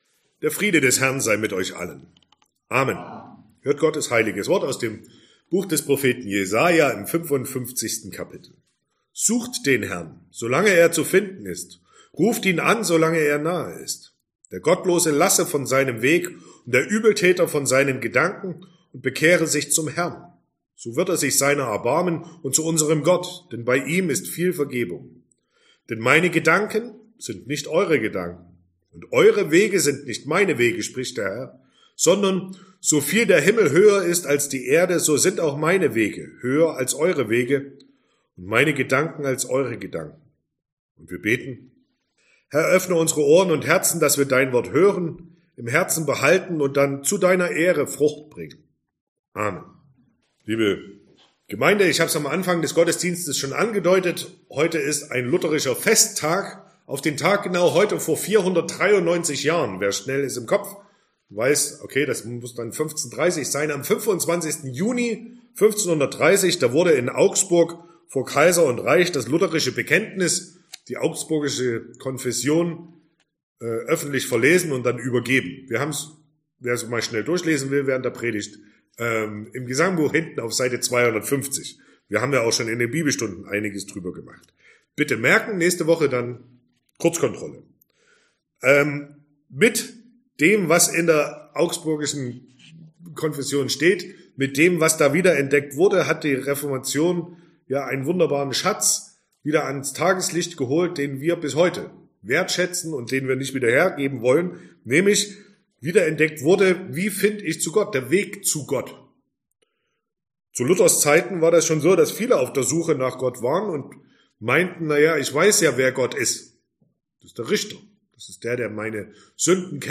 Sonntag nach Epiphanias Passage: Jesaja 55, 6-9 Verkündigungsart: Predigt « 2.